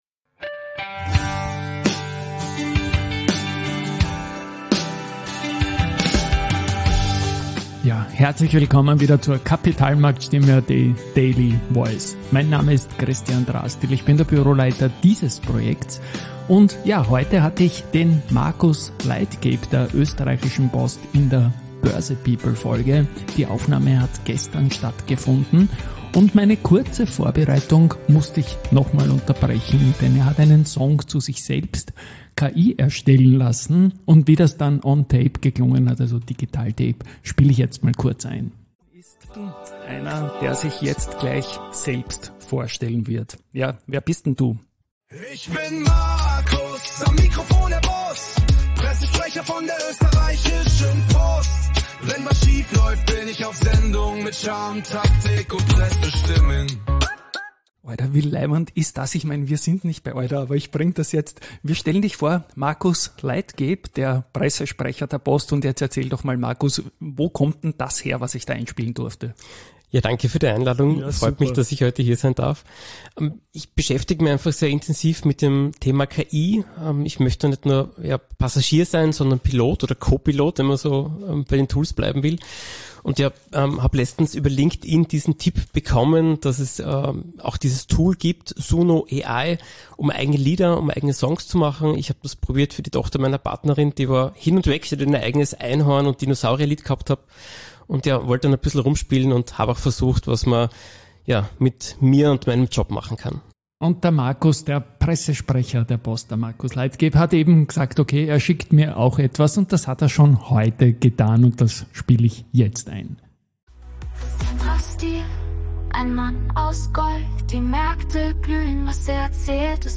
Und er hat mir auch einen KI-Song erstellt.